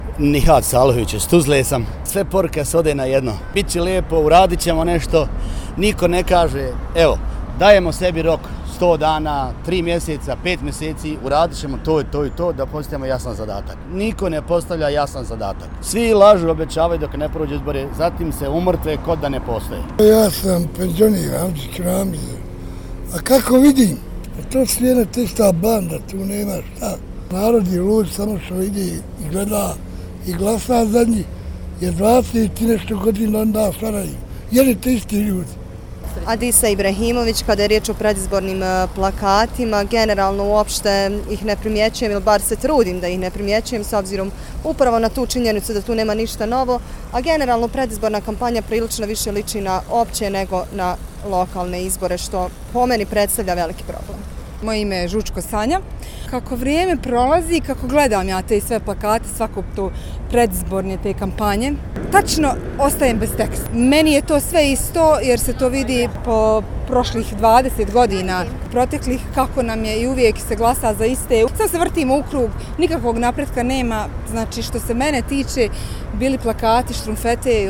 Anketa: Stavovi građana o predizbornim porukama i obećanjima